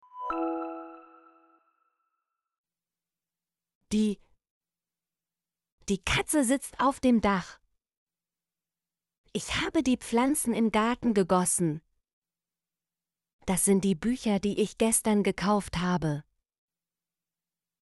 die - Example Sentences & Pronunciation, German Frequency List